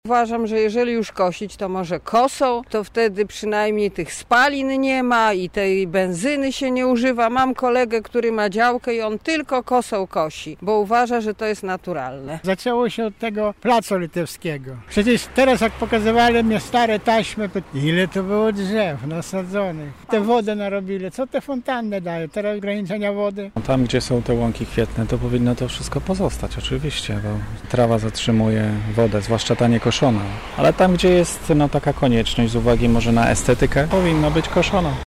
[SONDA] Co lublinianie sądzą o wycince zieleni w naszym mieście?
Zapytaliśmy przechodniów, co sądzą na ten temat: